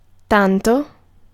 Ääntäminen
US : IPA : /ˈsoʊ/